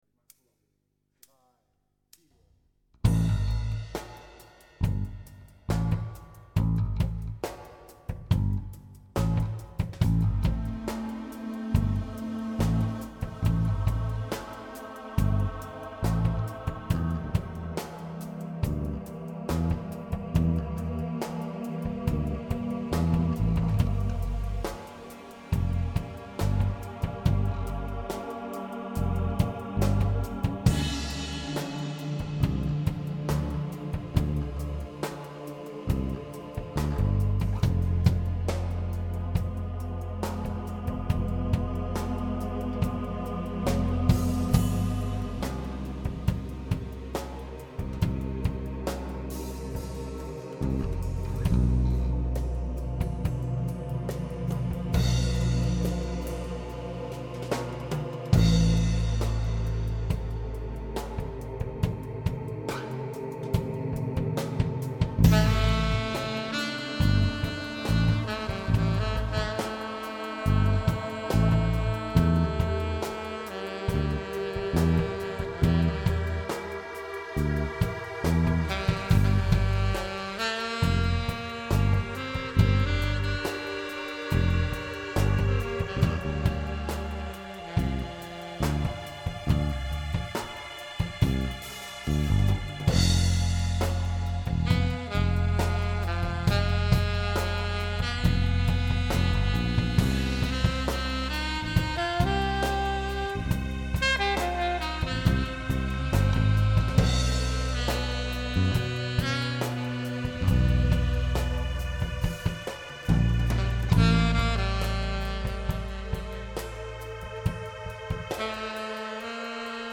ist alles live.
Der Rest ist Improvisation.
Zu Jazz-Rock führt einen die natürliche drummerevolution irgendwann zwangsläufig, wenn nicht, ist irgendwas schief gelaufen :P
klingt, vor allen dignen für einen mitschnitt sehr sehr gut.
man merkt, dass ihr gut mit einander harmoniert - keiner spielt sich in den vordergrund und so wirkt es meiner meinung nach insgesamt sehr stimmig. auch die jeweils gewählten sounds bei bass und keyboard passen gut.
Trotzdem - macht Spass zu hoeren, vor allem fuer eine Impro.